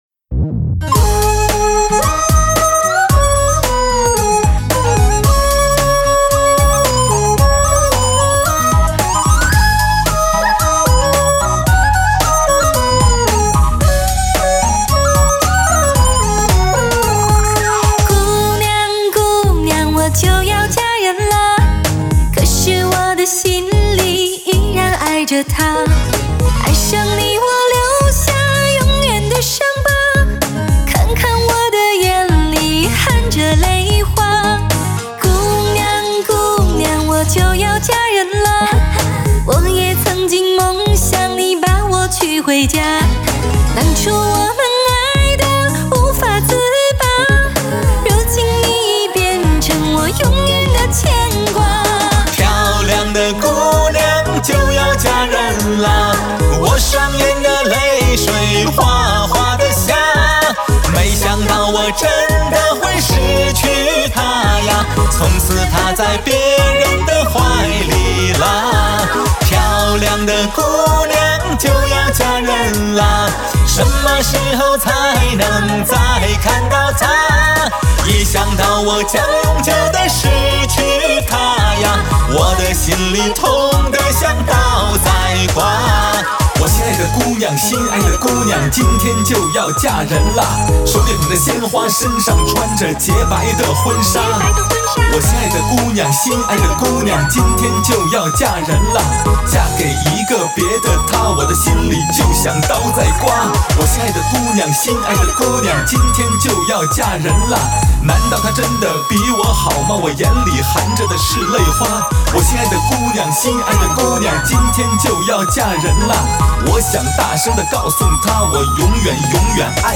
唱片类型：汽车音乐
24K德国HD金碟，采用极品发烧级的方芯线材，真空管麦克风和电源处理器录制。